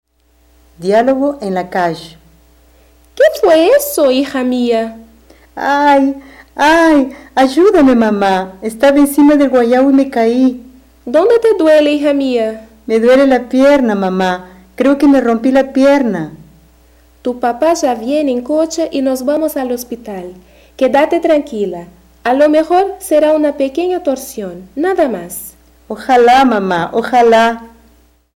Diálogo IX: Na rua
Description: Áudio do livro didático Língua Espanhola I, de 2008. Diálogo com palavras referentes as partes do corpo.